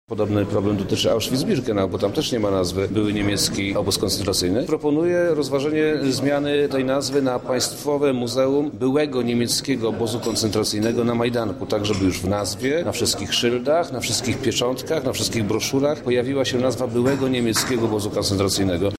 Szczegóły swojej decyzji tłumaczy wojewoda lubelski, Przemysław Czarnek